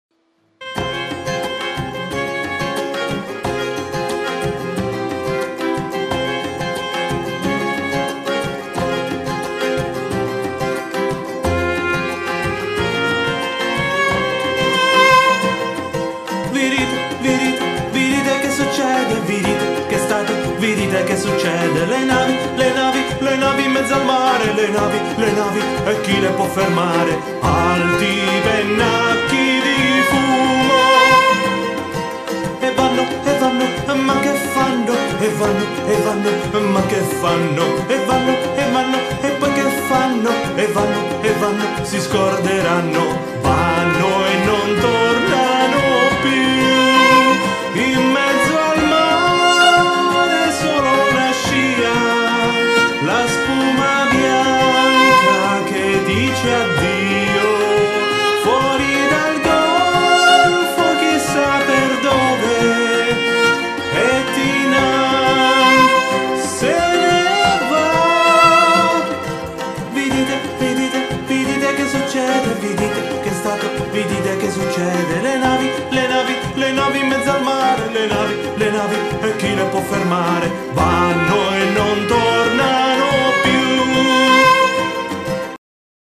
Si sentono voci, concitate uno squillo di tromba .. i soldati corrono sugli spalti di qua e di là   guardando all’orizzonte